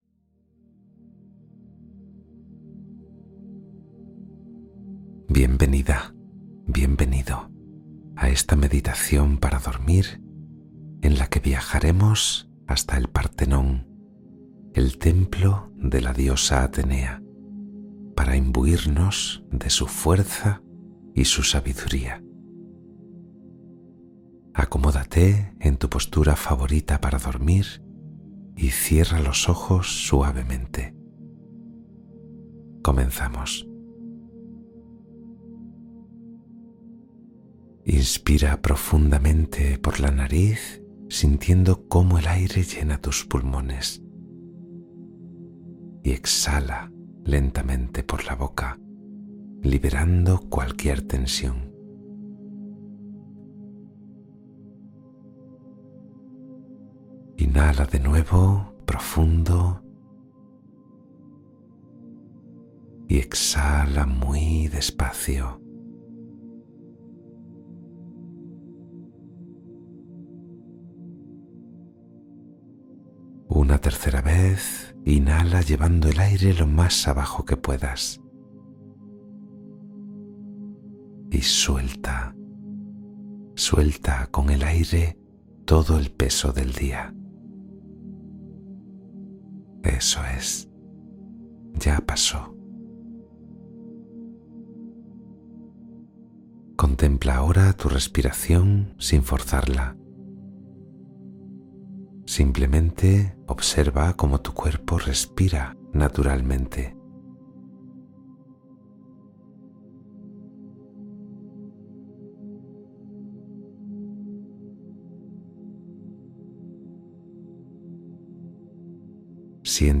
Cuento guiado para dormir profundamente y sanar tu ser interior